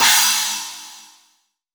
Index of /90_sSampleCDs/AKAI S6000 CD-ROM - Volume 3/Crash_Cymbal2/CHINA&SPLASH